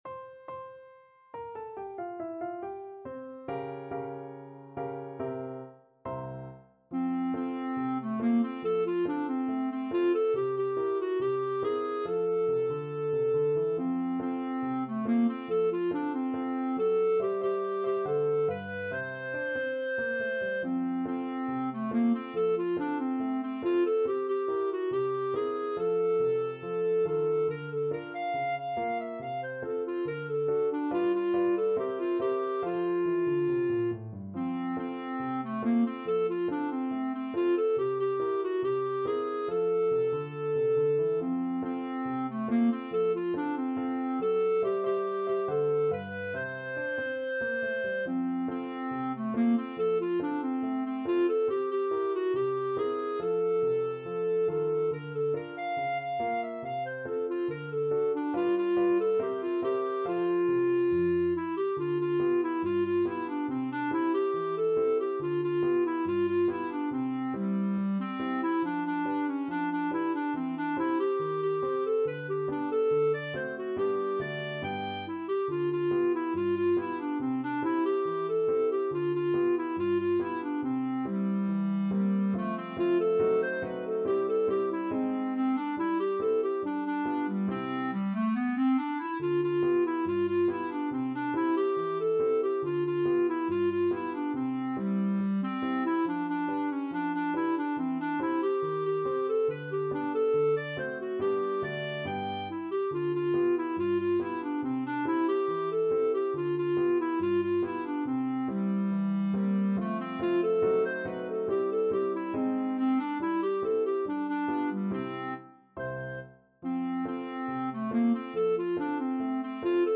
2/4 (View more 2/4 Music)
Not fast Not fast. = 70
Jazz (View more Jazz Clarinet Music)